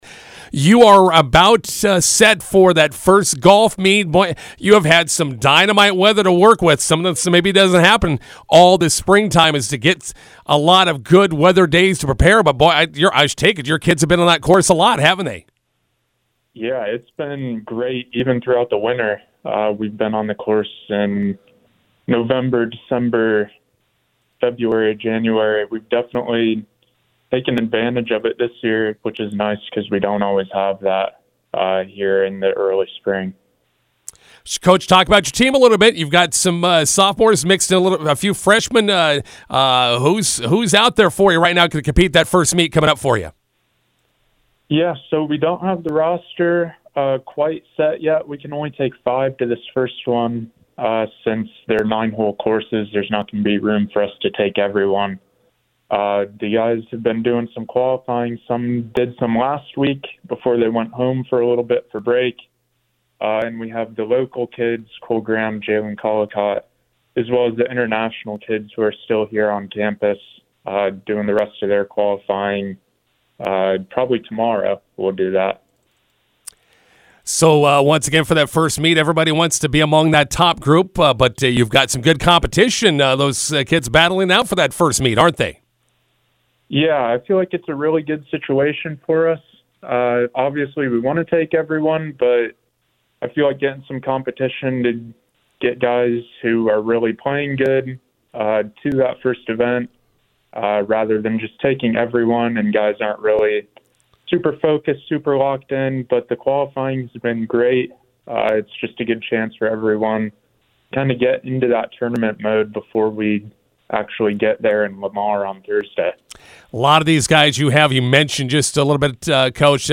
INTERVIEW: McCook Community College golfers compete at the Lamar/Otero Invite to kick off their spring season.